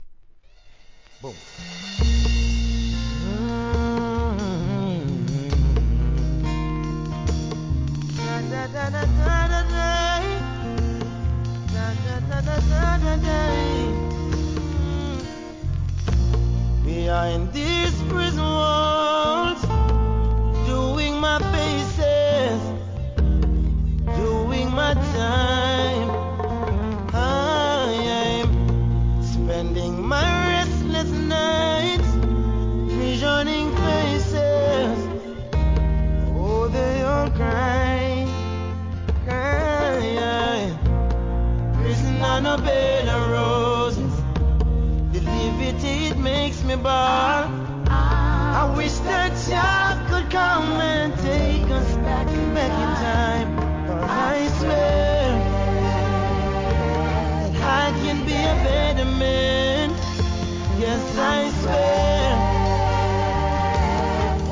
REGGAE
アコースティックとジャンベRHYTHMでの2005年大ヒット!!